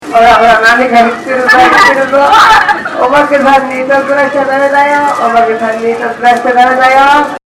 祭りの中MacBookを持って録音をしてきました
予想以上に内臓マイクの音質が良かったのですが、